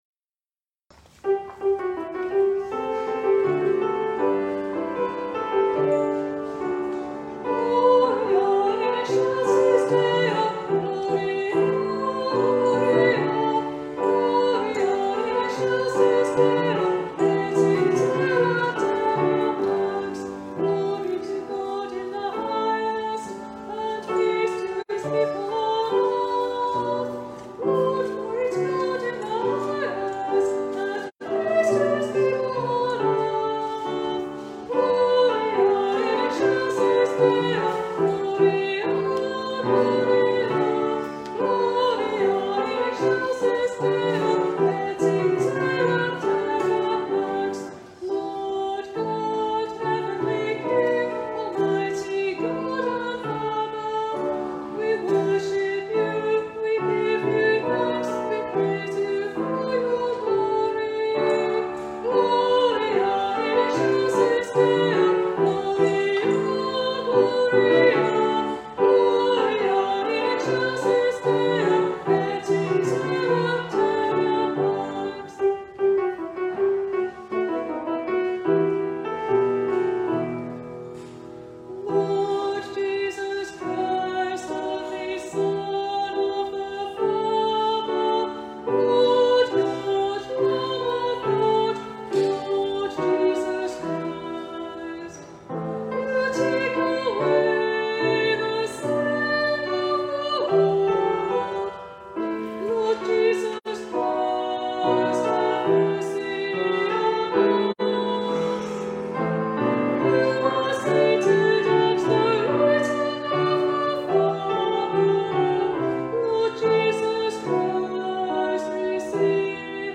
Whilst communion was being distributed